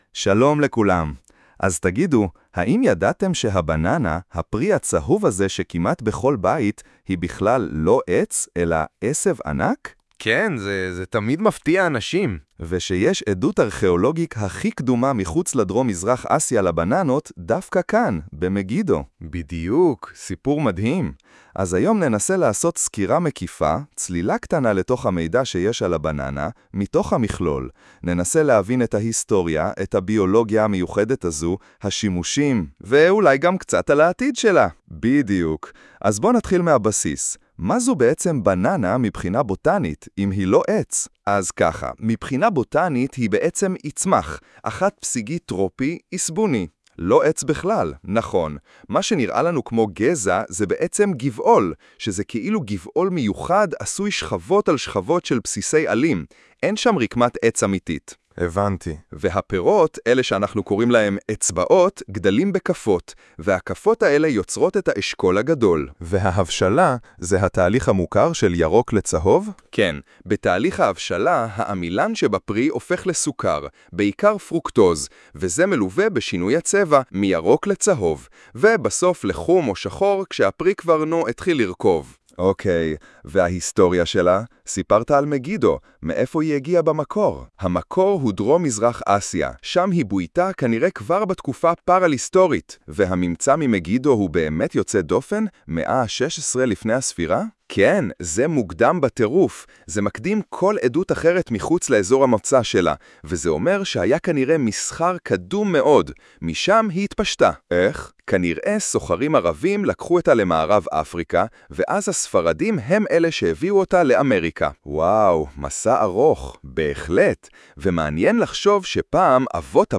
ואנחנו נשתמש בפיצ'ר החדש בגוגל AI סטודיו – הקראה של טקסט בעברית באמצעות AI.
אז בכותרת העליונה "Style instructions" נכתוב את סגנון הטקסט שלפנינו – ובמקרה שלנו – 2 גברים מדברים בנינוחות בפודקאסט.
הפודקאסט מוכן - עם קולות של 2 גברים.